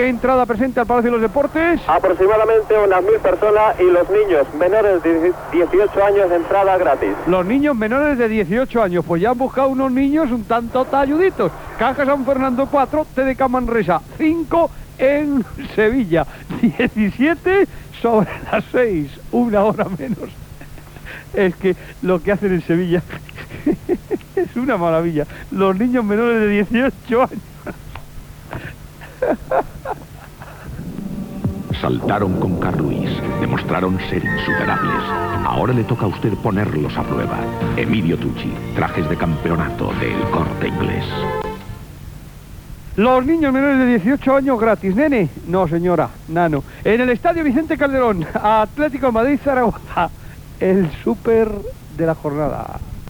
Connexió amb el Palau d'esports de Sevilla on es juga el partit de bàsquet masculí entre Caja San Fernando i TDK Manresa. Publicitat.
Esportiu